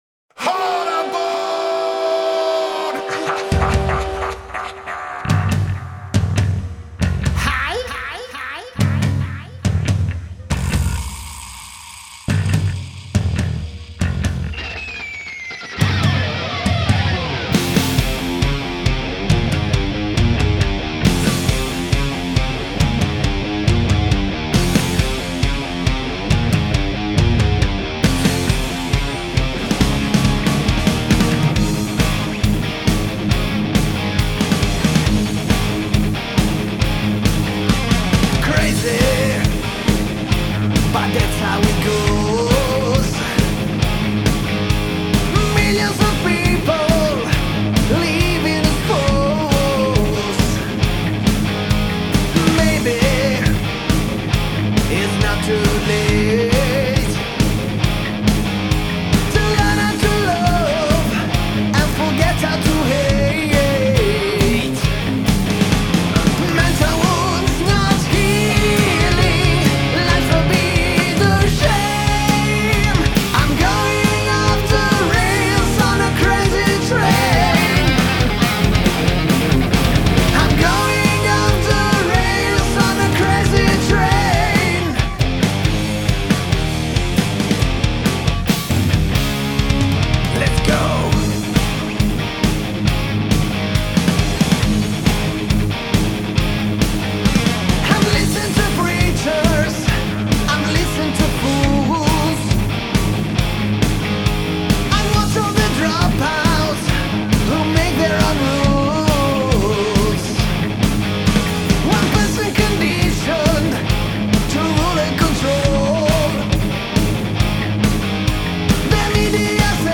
Lead vocals
Guitars, Vocals
Bass
Drums, Vocals